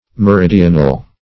Meridional \Me*rid"i*o*nal\, a. [F. m['e]ridional, L.